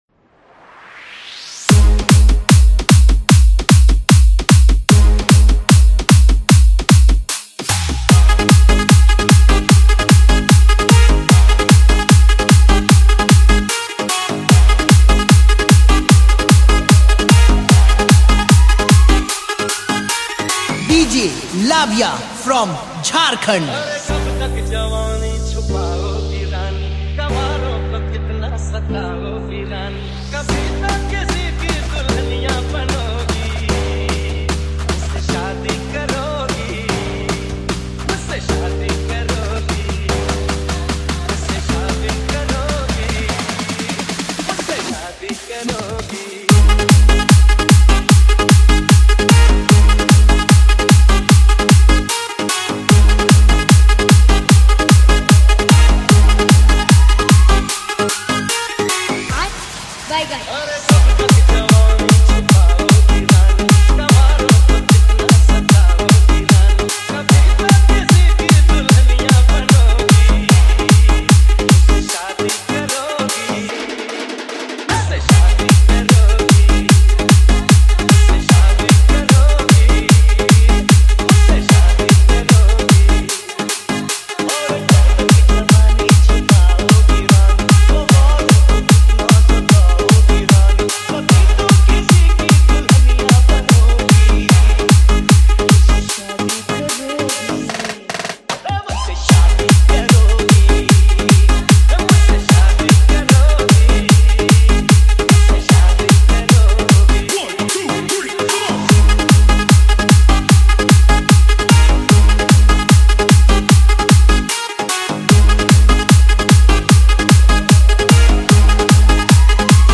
Category : Weeding Special Remix Song